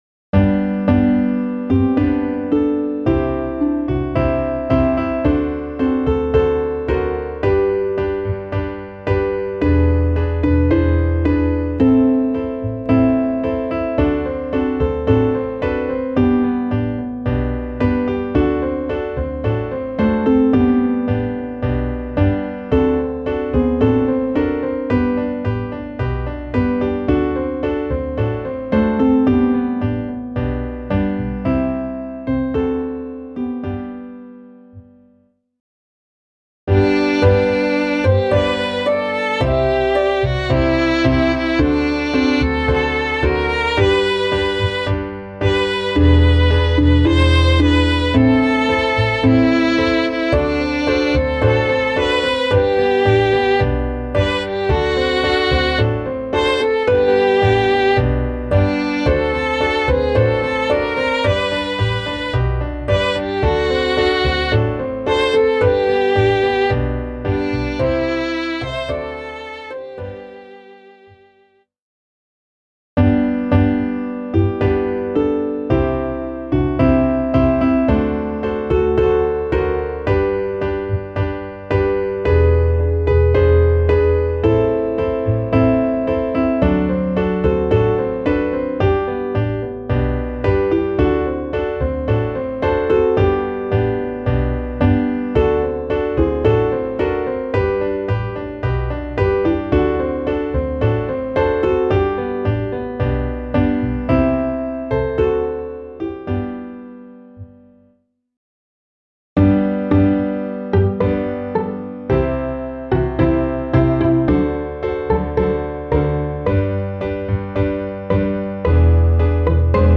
Klaviersatz 4/4